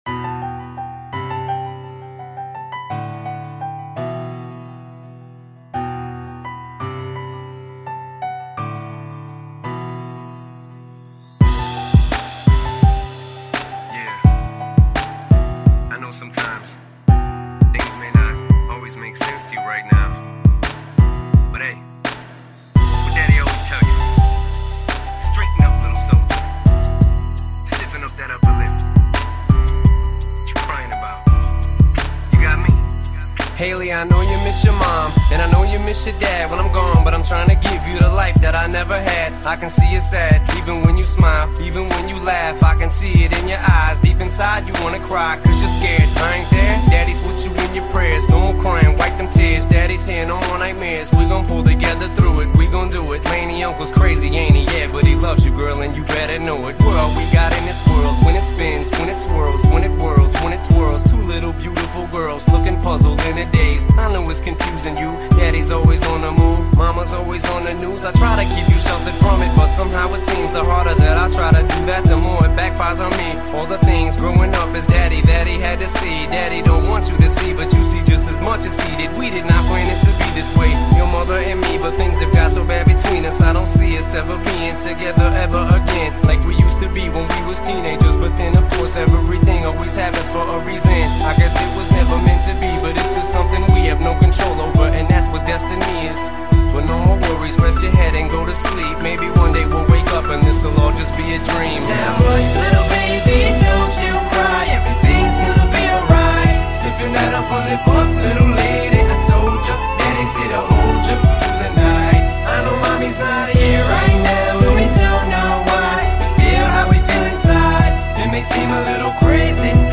Жанр: Rap/Hip-Hop